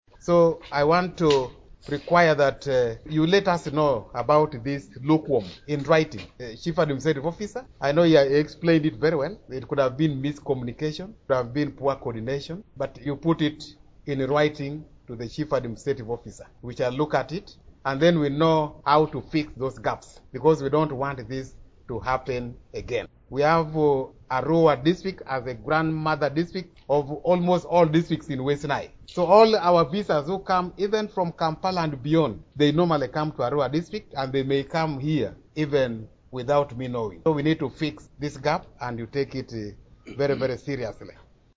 The decision came during the commissioning of the new staff house at the facility.